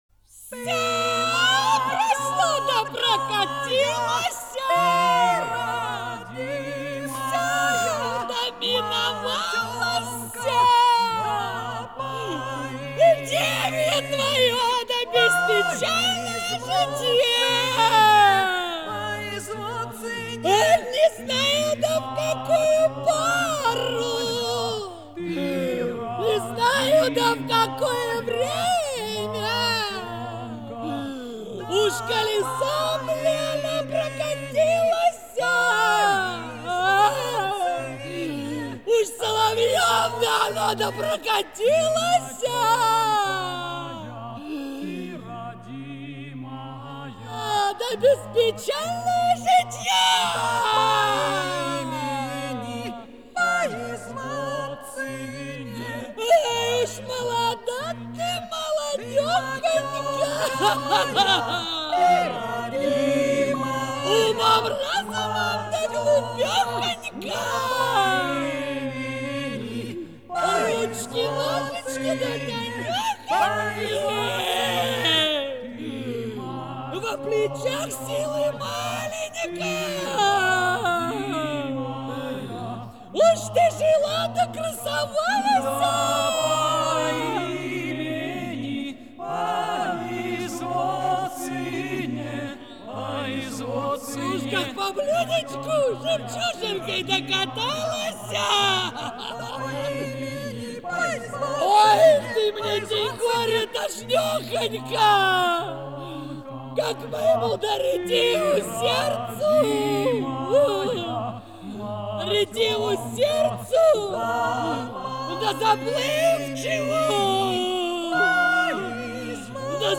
Жанр: Rock, Pop
Формат: CD, Stereo, Album, Compilation
Стиль: Art Rock, Vocal
Сюита на темы народных песен